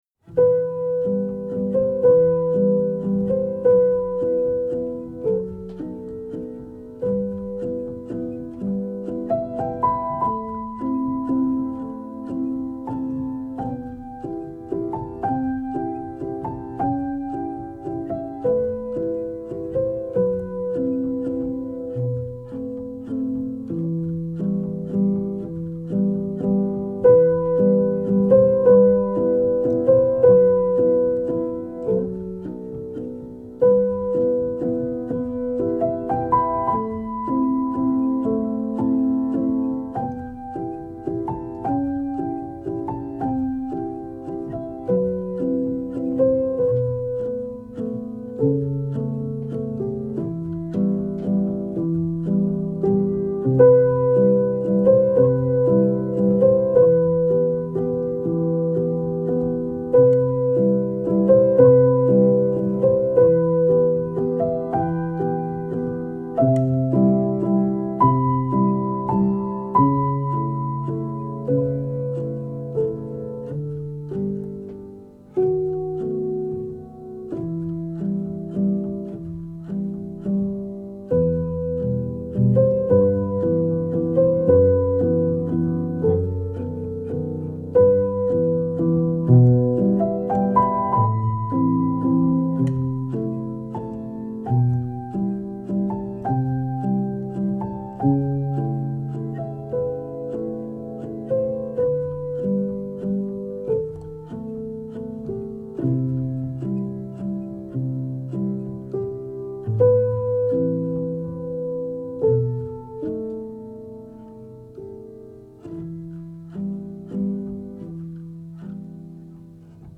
آرامش بخش , پیانو , عاشقانه , مدرن کلاسیک , موسیقی بی کلام